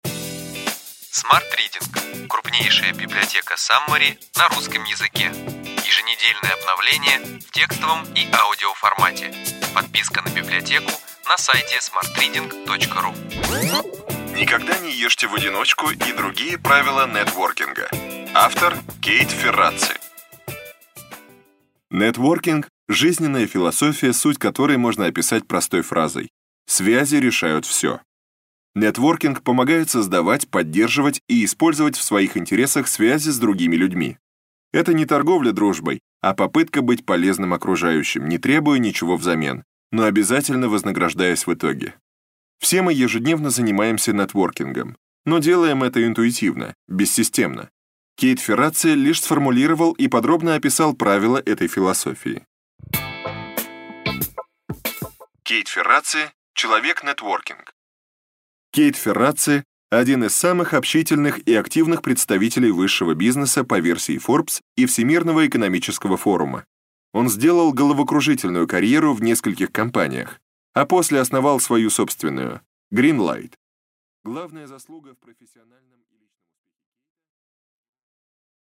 Аудиокнига Ключевые идеи книги: Никогда не ешьте в одиночку и другие правила нетворкинга.